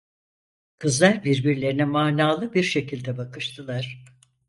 manalı a 🐌 Meaning Concepts Synonyms Translations Notes Extra tools meaningful, significant purposeful, pointful Opposite of manasız, anlamsız Pronounced as (IPA) /maː.naːˈɫɯ/ Etymology By surface analysis, mana + -lı.